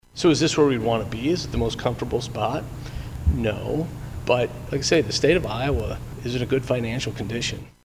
KRAIG PAULSEN, THE GOVERNOR’S BUDGET DIRECTOR, SERVES ON THE PANEL AND SAYS REPUBLICAN LAWMAKERS WHO PASSED THE TAX CUTS PLANNED FOR THIS, SOCKING AWAY SIX BILLION DOLLARS’ WORTH OF TAXES OVER THE PAST SEVERAL YEARS THAT CAN BE SPENT TO BALANCE THE STATE BUDGET.